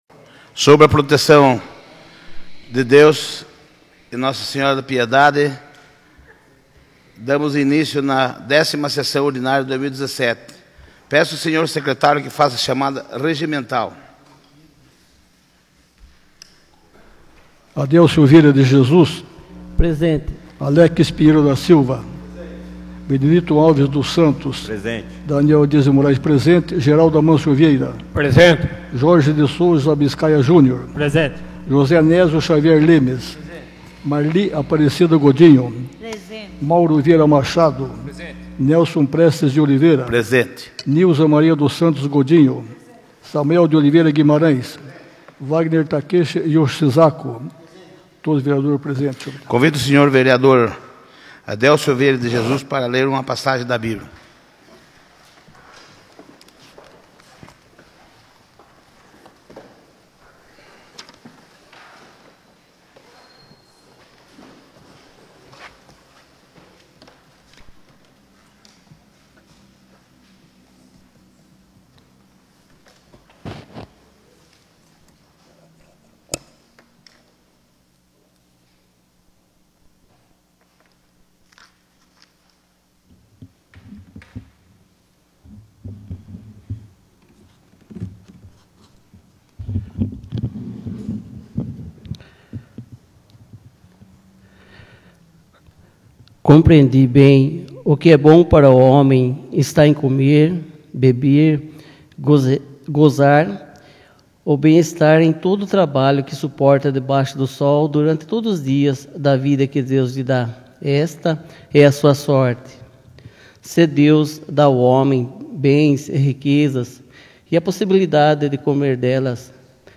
10ª Sessão Ordinária de 2017